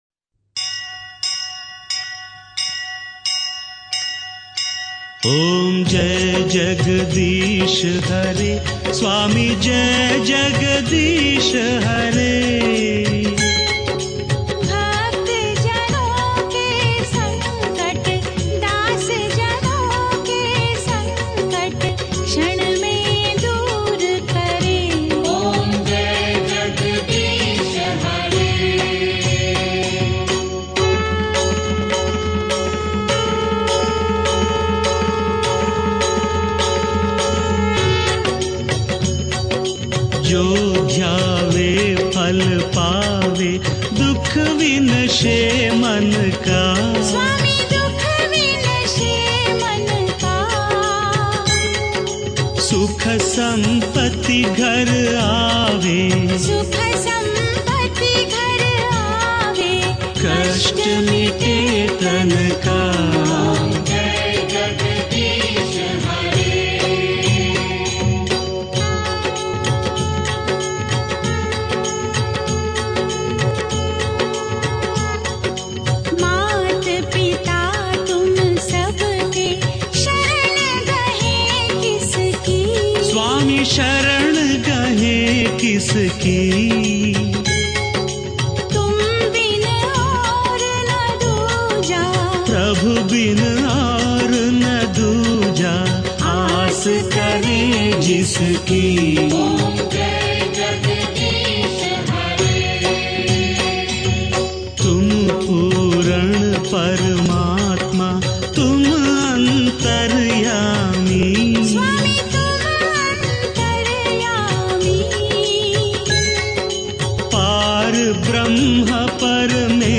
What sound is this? Media Files : Aartis